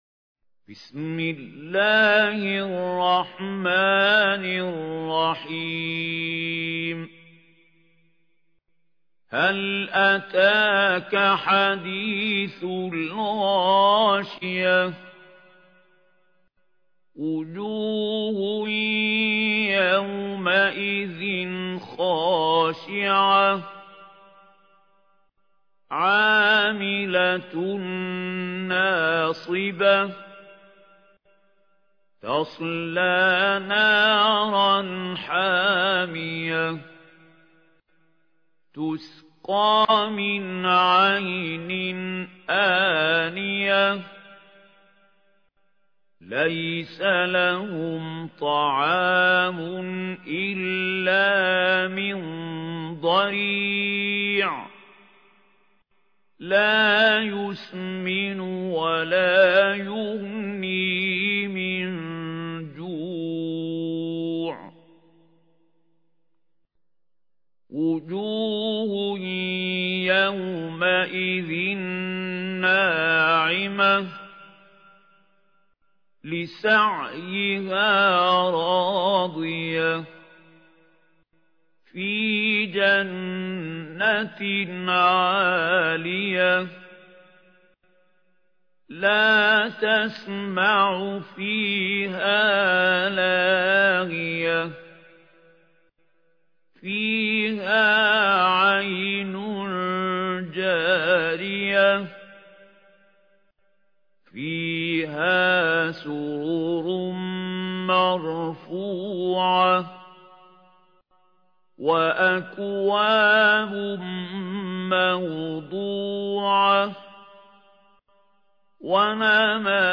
ترتيل
سورة الغاشية الخطیب: المقريء محمود خليل الحصري المدة الزمنية: 00:00:00